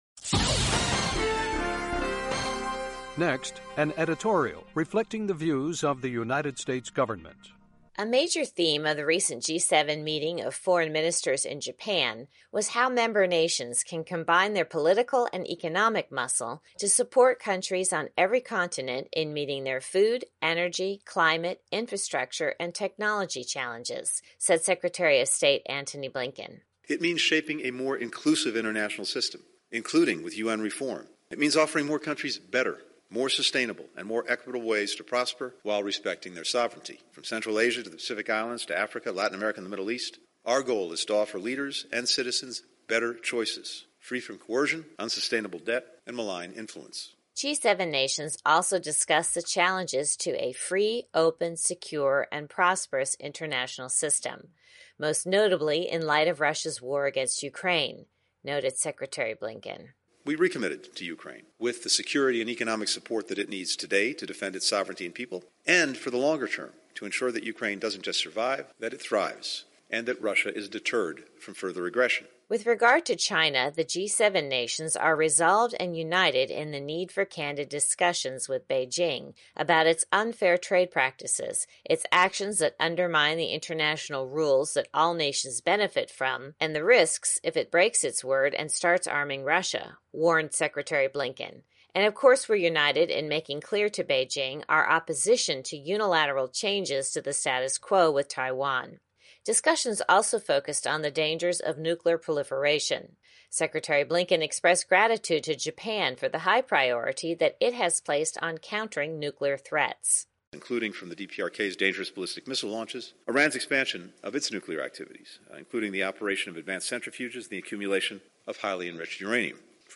Uvodnik koji odražava stav Vlade SAD